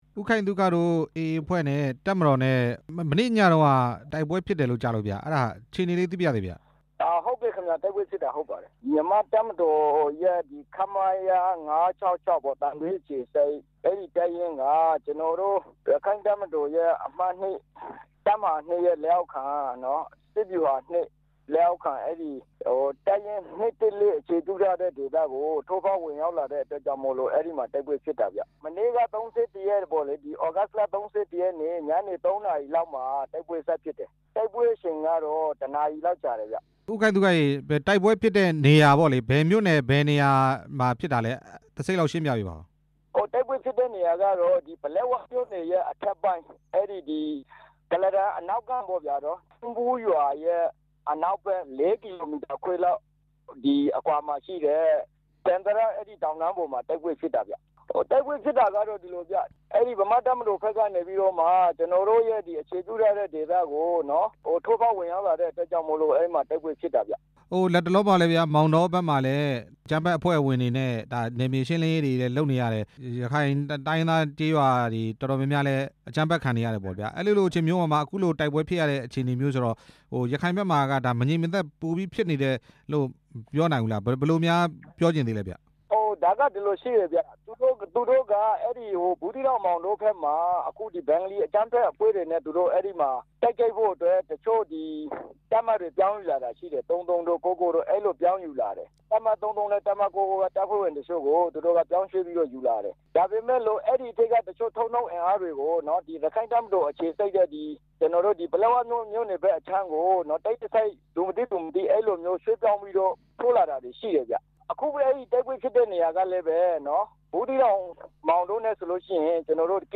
က ဆက်သွယ်မေး မြန်းထားပါတယ်။